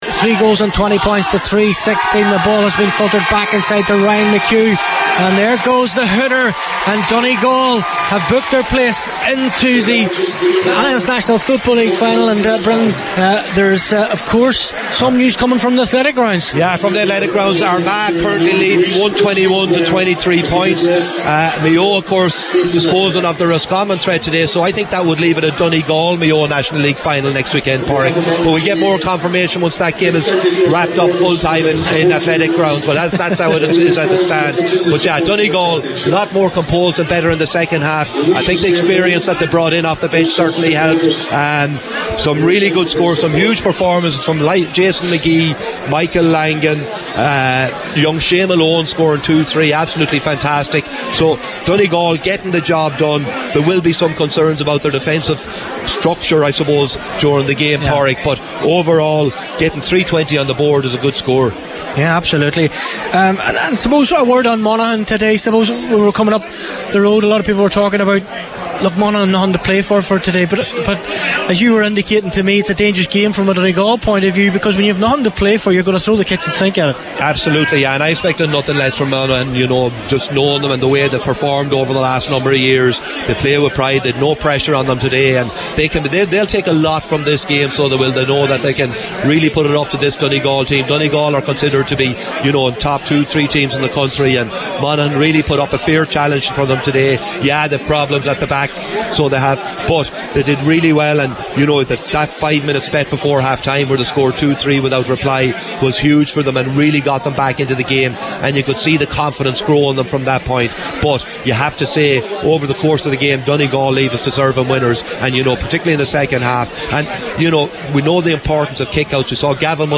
Post-Match Reaction
were live at full time in Clones for Highland Radio Sunday Sport…